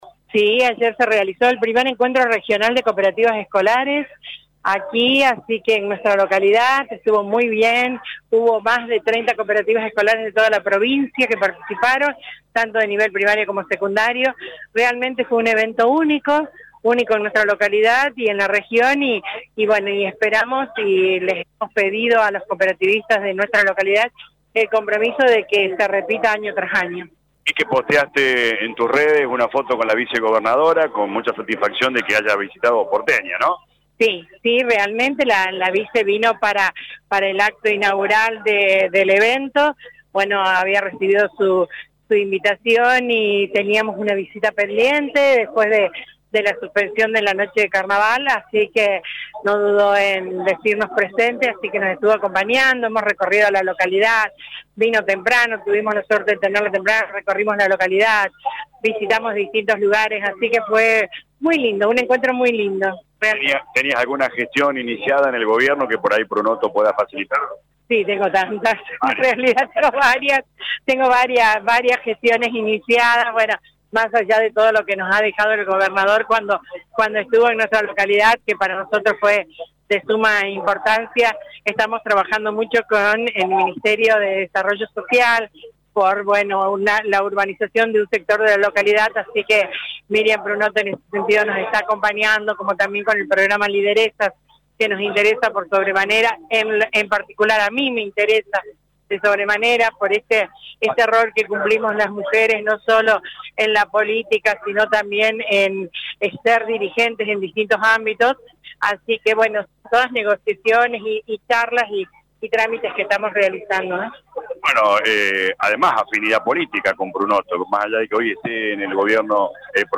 🔊 Nora Passero habló con LA RADIO luego de recibir a la vicegobernadora Miriam Prunotto en Porteña